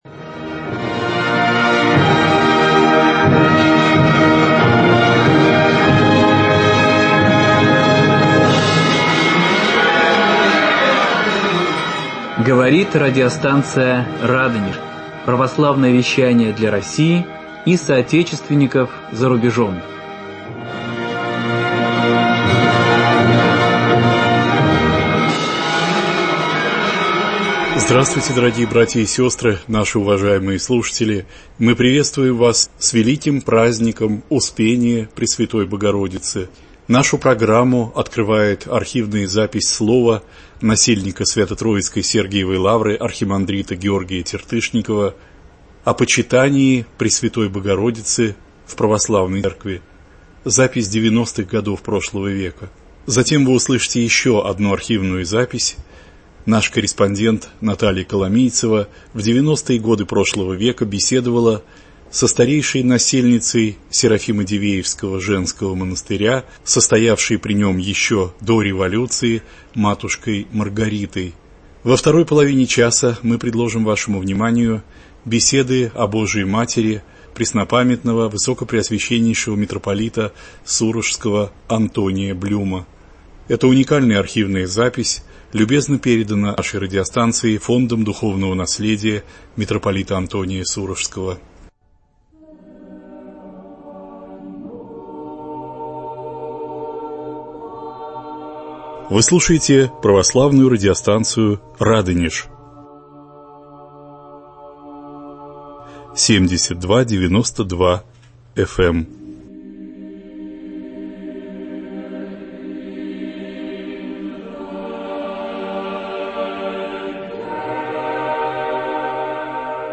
Праздничная программа из архивных проповедей на Праздник Успения Пресвятой Богородицы.